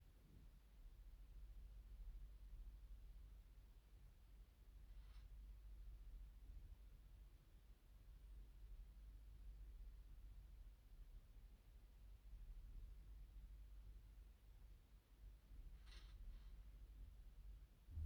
Bruit récurrent tuyauterie chambre
Le bruit s'entend dans tout l'appartement, et principalement dans la chambre où se trouve la trappe avec les canalisations.
- Un bruit de frottement léger et court qui revient tous les 20s-40s, sans rien faire. Il semble provenir de mon arrivée d'eau et également de la colonne en métal. Il diminue si je coupe mon eau mais s'accentue à l'heure de pointe de l'immeuble.
Cf audio "bruit 1" à 4s et surtout 15s